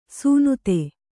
♪ sūnute